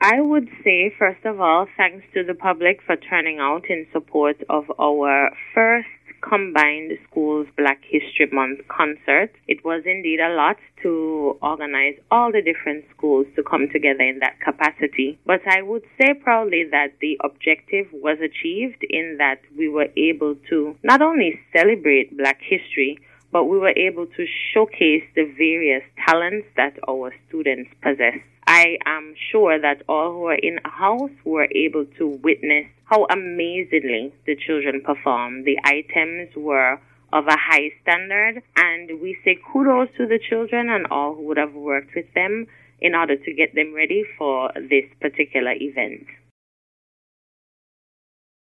She told the VONNEWSLINE there is the possibility of a similar show next year.